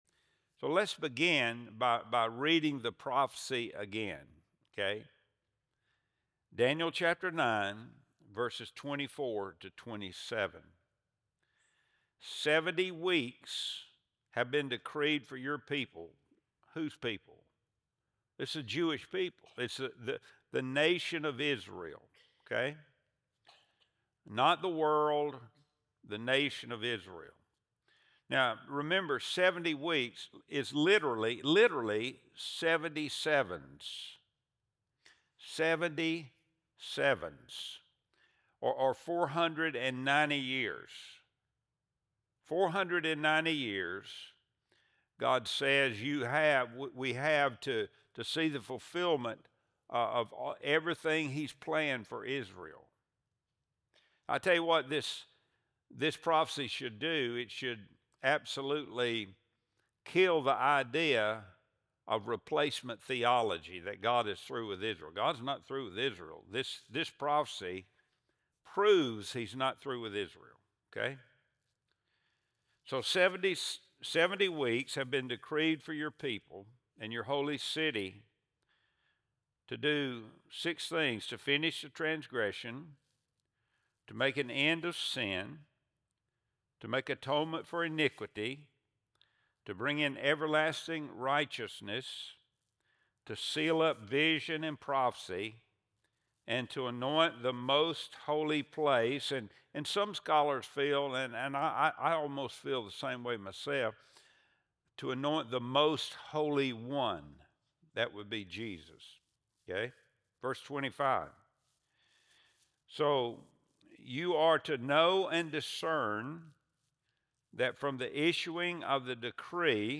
Wednesday Bible Study Series | December 17, 2025